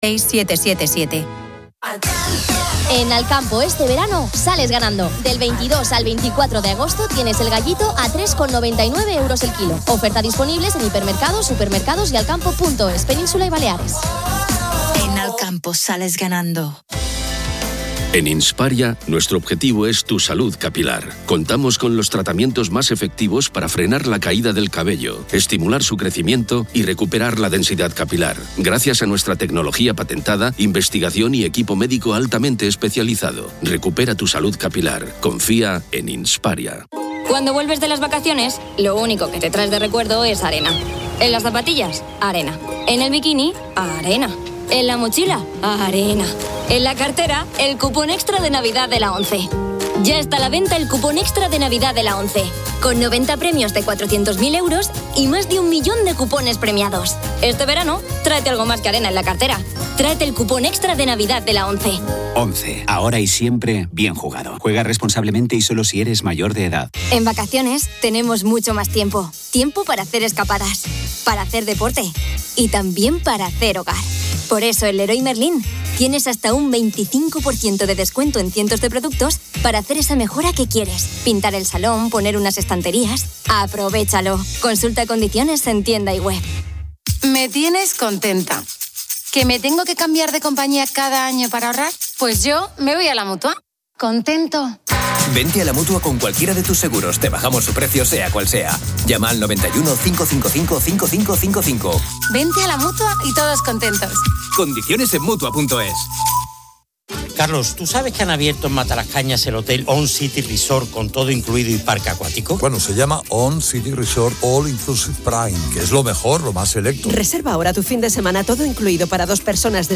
El audio incluye publicidad de supermercados (Alcampo, Carrefour, Leroy Merlin), seguros (La Mutua), ocio (ON City Resort), y loterías (ONCE). Las noticias se centran en los **incendios forestales** que han devastado más de 400.000 hectáreas en España, con un tercio siendo intencionados, y la complejidad de las investigaciones (SEPRONA). También se abordan las frecuentes **incidencias en la red ferroviaria**, como la suspensión del AVE Madrid-Galicia, afectando a miles de pasajeros.